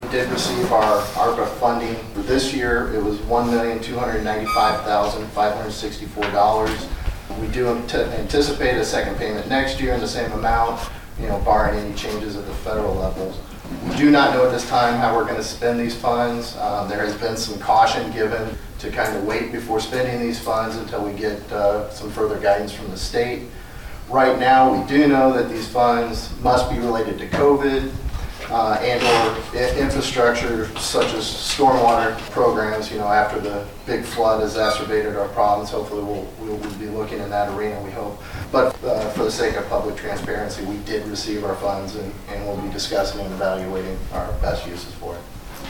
The city of Marshall applied to get more than $2.5 million total earlier this year, and Ward 1 Councilman Leon Thompson gave an update on the funding at the council meeting on Tuesday, September 7.